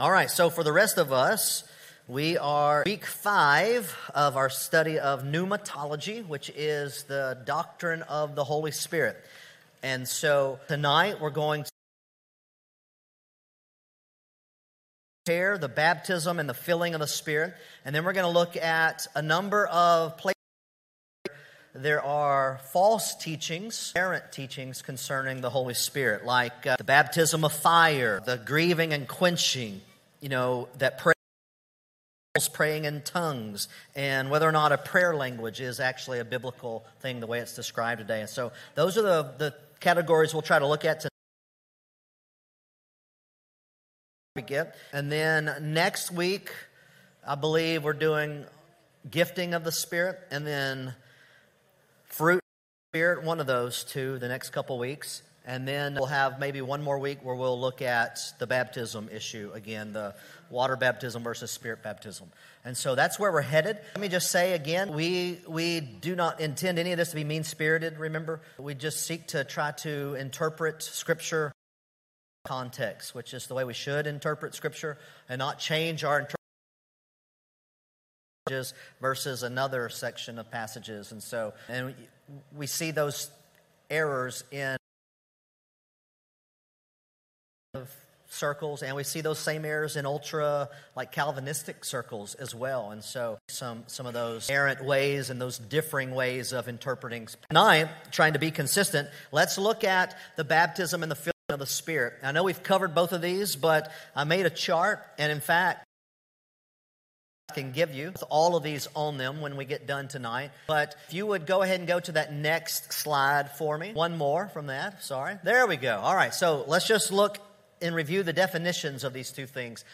Sermons from the ministry of Grace Point at Eagle Heights Church in Orange, TX.